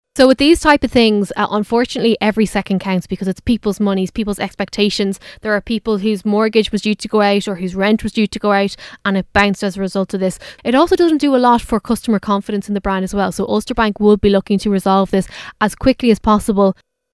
Tech expert